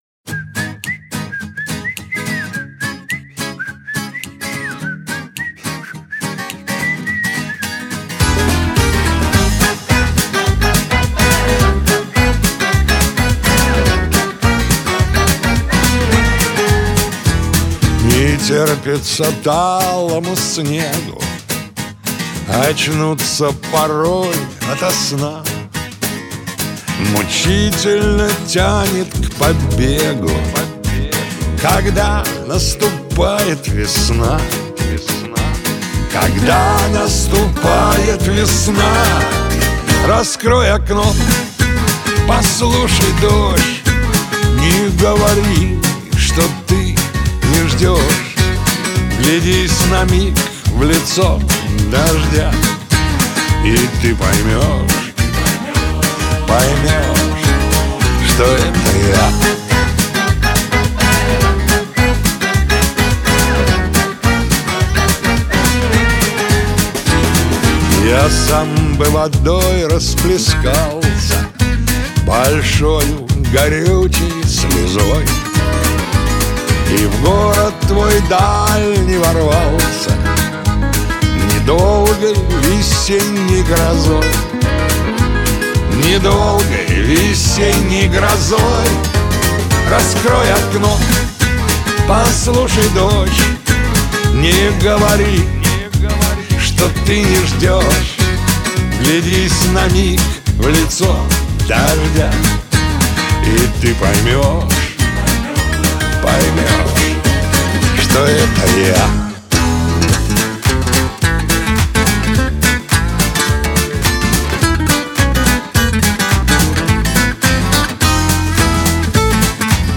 Скачать музыку / Музон / Музыка Шансон